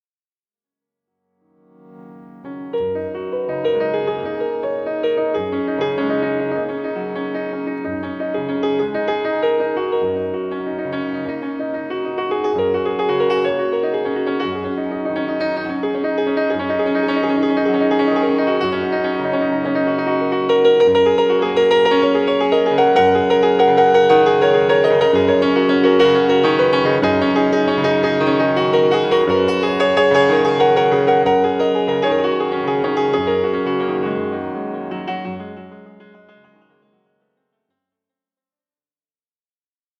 Entre grégorien et gospel, le piano chante Marie.